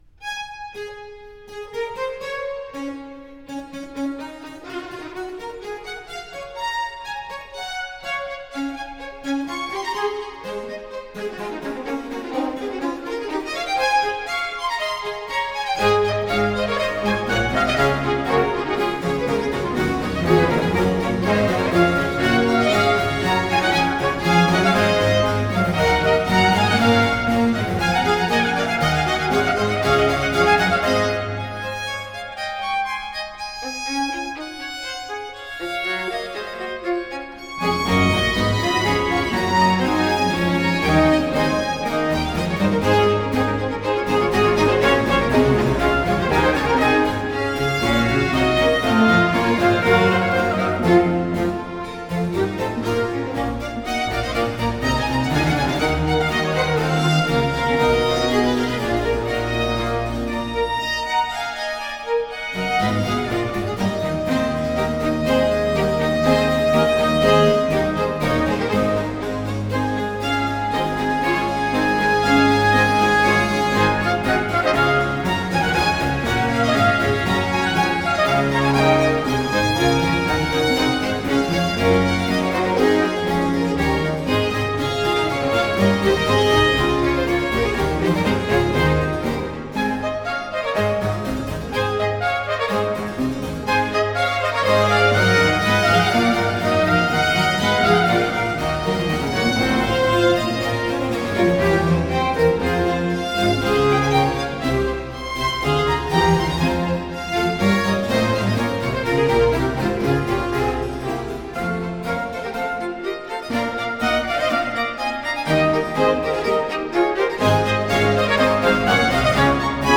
Allegro.